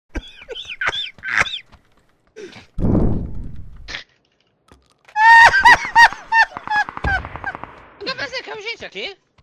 Play risada fofo, Download and Share now on SoundBoardGuy!
risada-fofo.mp3